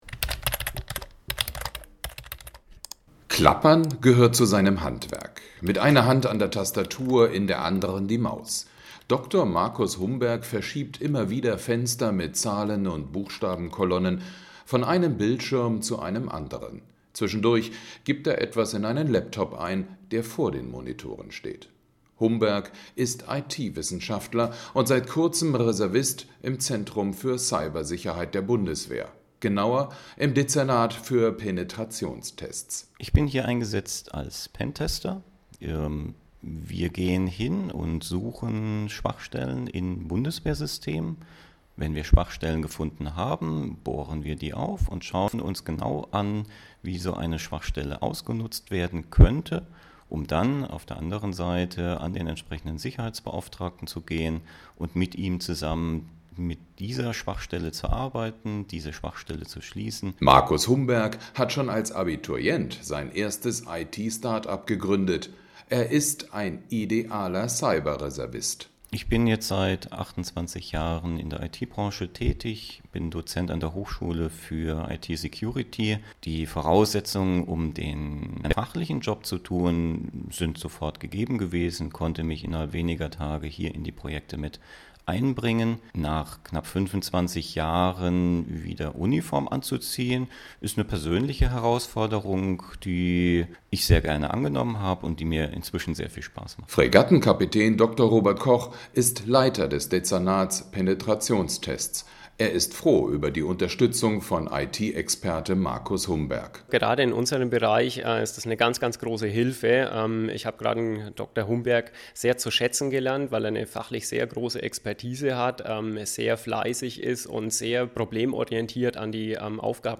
Soldat in einer Interviewsituation